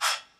Vox
Murda Chant.wav